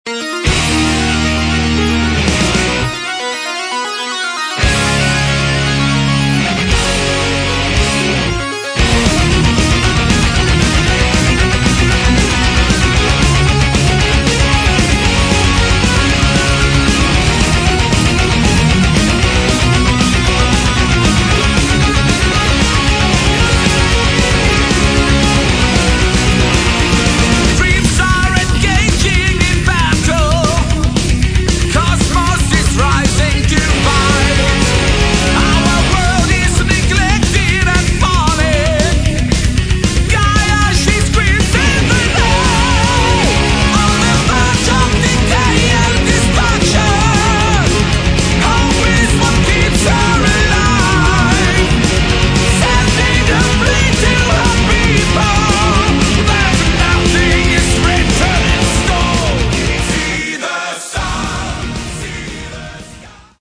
Metal
вокал
барабаны
клавиши
гитара
бас